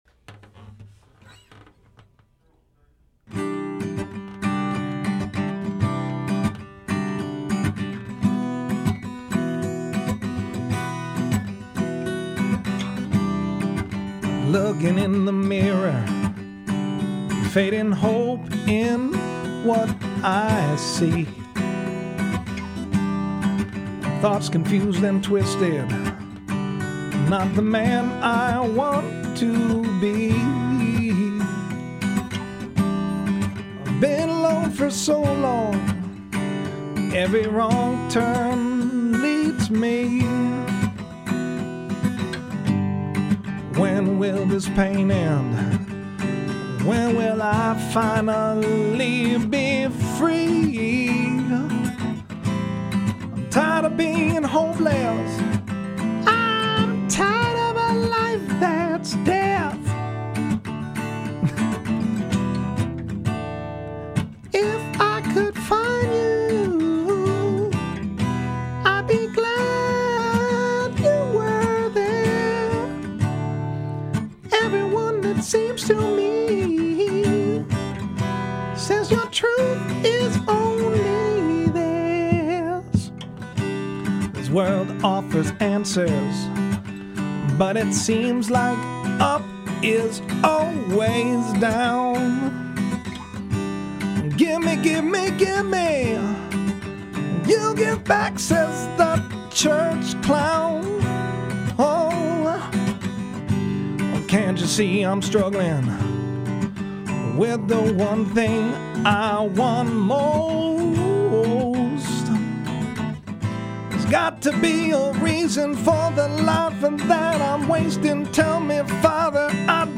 Before you read this poem, if you want take the time and listen to this original song I wrote.
but I wanted to sing it because this was ten years ago